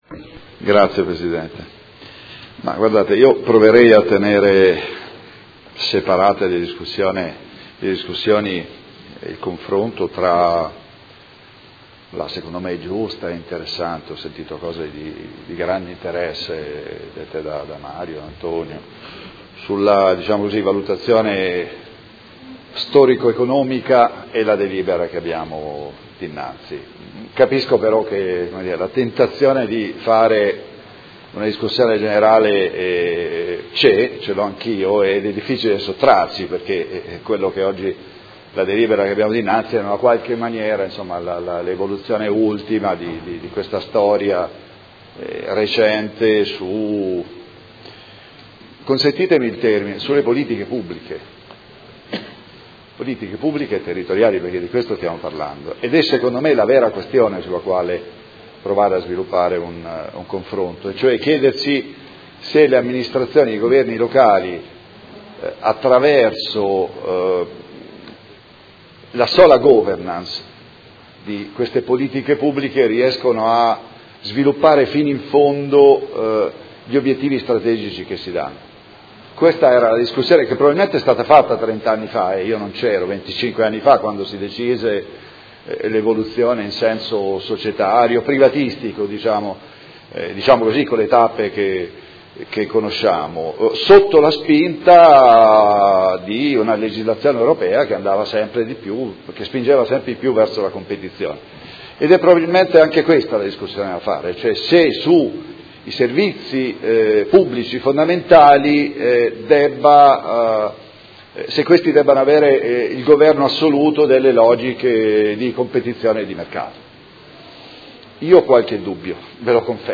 Seduta del 5/04/2018. Dibattito su proposta di deliberazione: Conclusione di Patti parasociali fra i Soci pubblici e fra i Soci pubblici di Area modenese di HERA S.p.A.